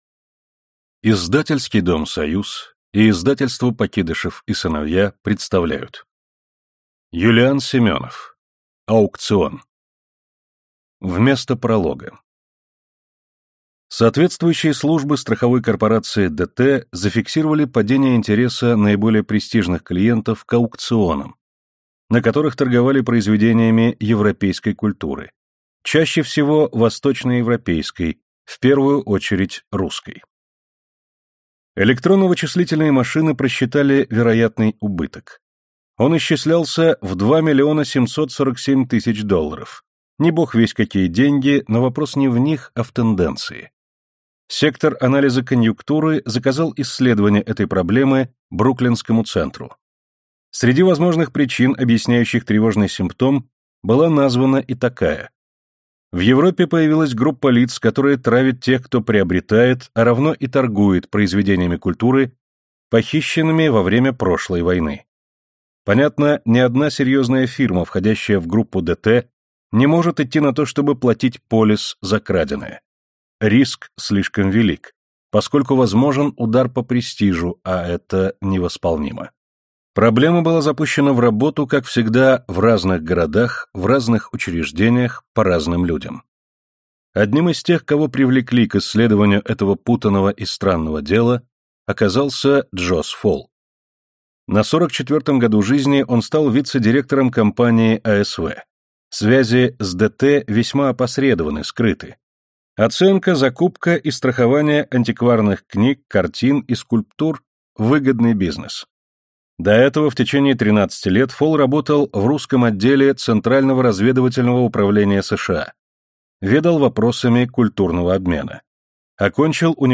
Аудиокнига Аукцион | Библиотека аудиокниг